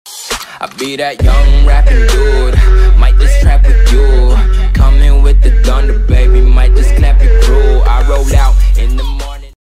bester-donation-sound-vezos-donatoion-sound_9guMQz3.mp3